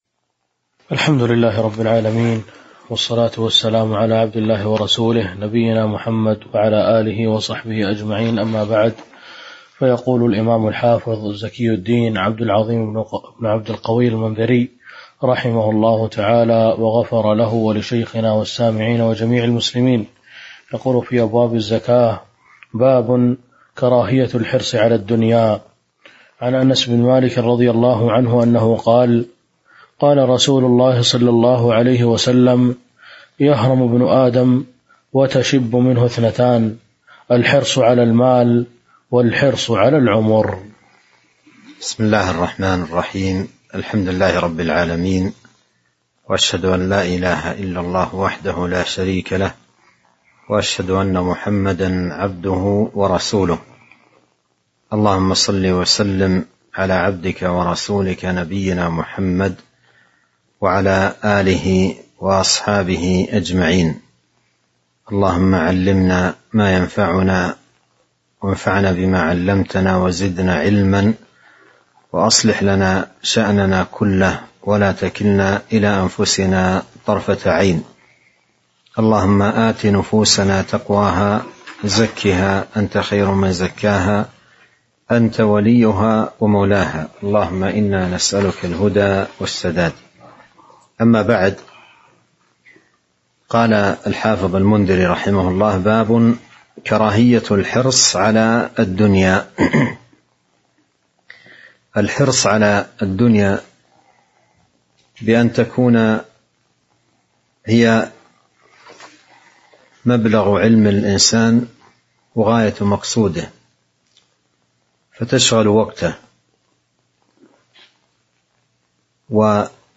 تاريخ النشر ٢٧ رجب ١٤٤٢ هـ المكان: المسجد النبوي الشيخ